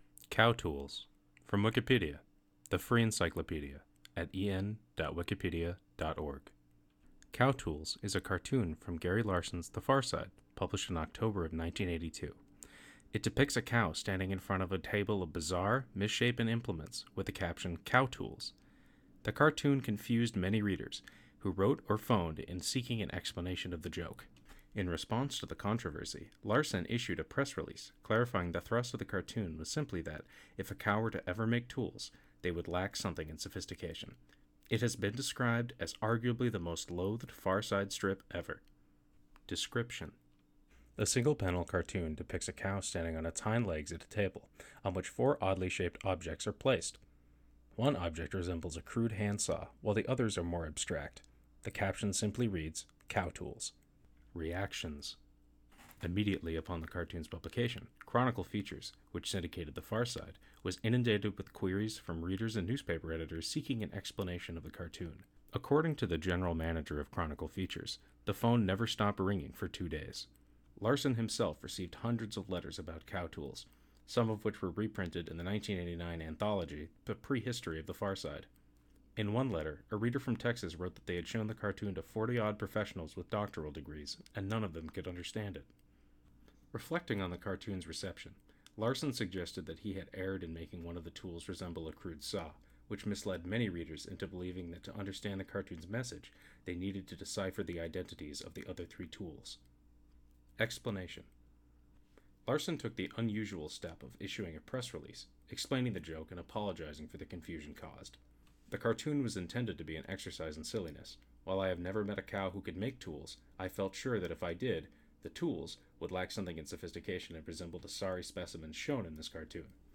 Captions English Audio reading for the Wikipedia page Cow Tools.